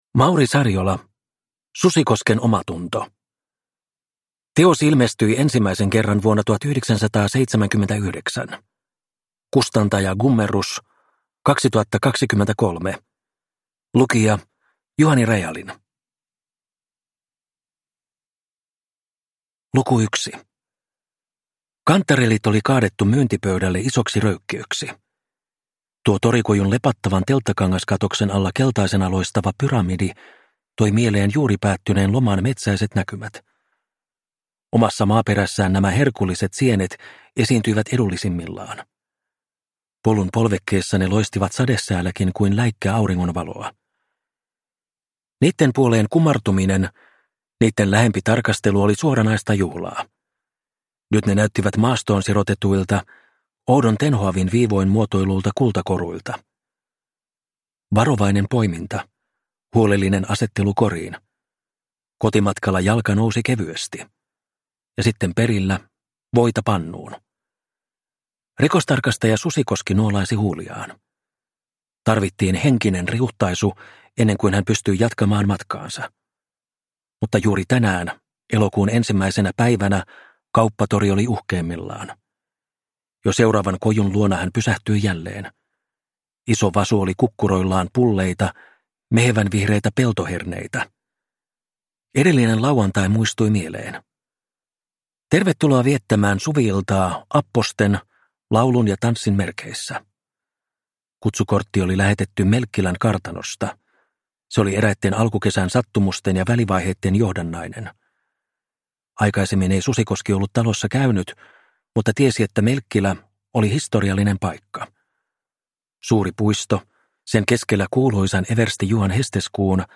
Susikosken omatunto – Ljudbok – Laddas ner